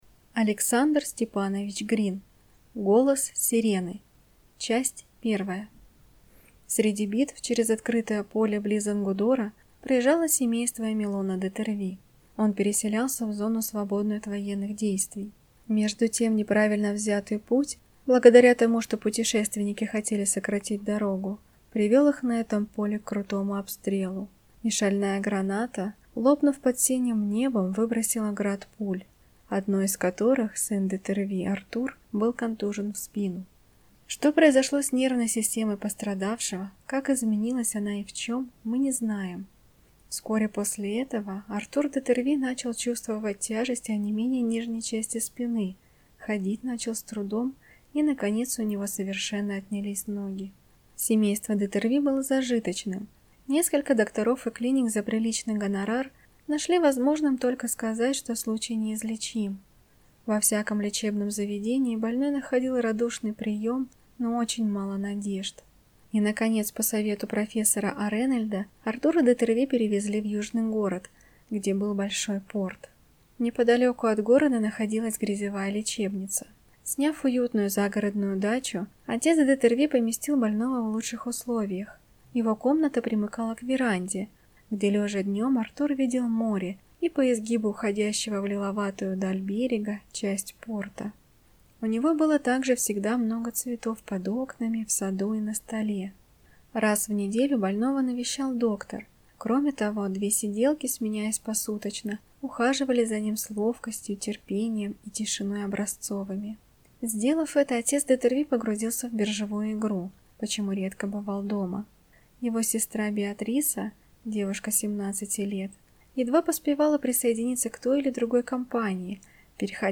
Аудиокнига Голос сирены | Библиотека аудиокниг